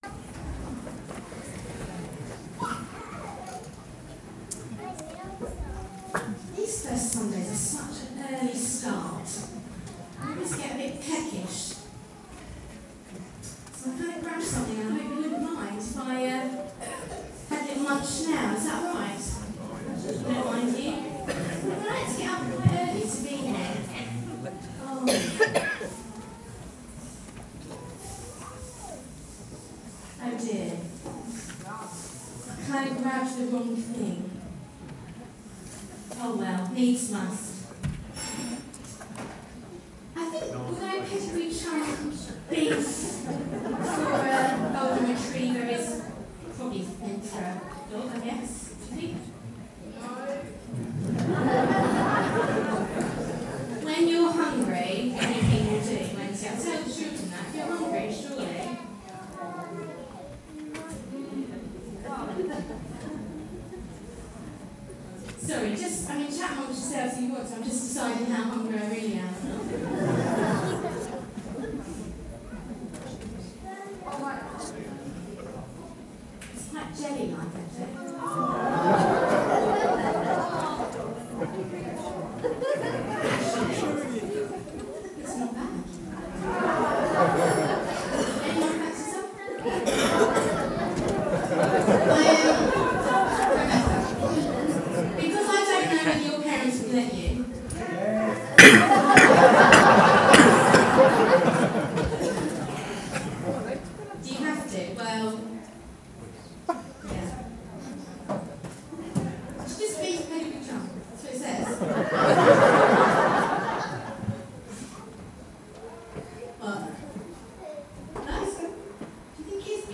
A message from the series "First Sunday."